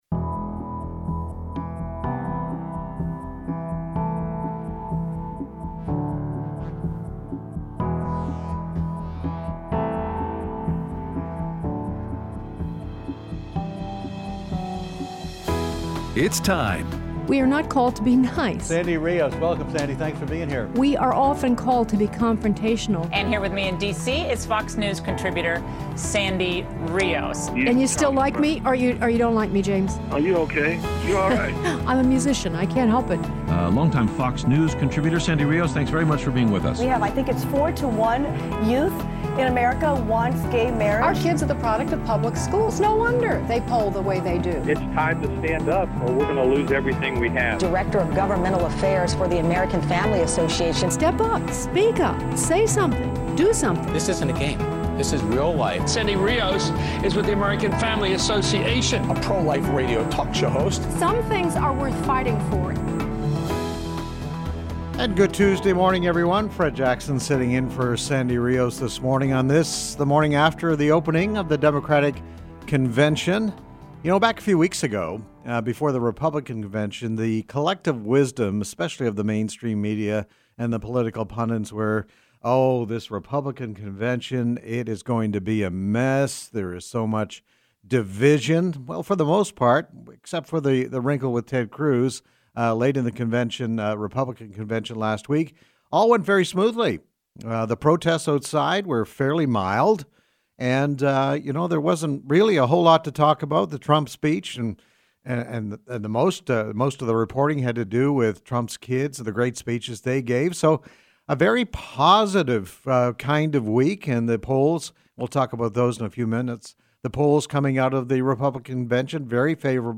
Guest Host
interview Gary Bauer on the DNC